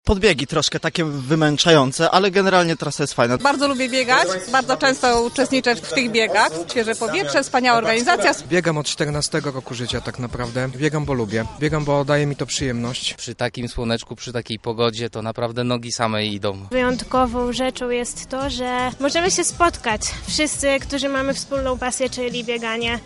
-podkreślają sami biegacze.
sonda-city.mp3